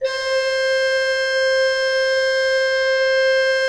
Index of /90_sSampleCDs/Propeller Island - Cathedral Organ/Partition L/HOLZGEDKT MR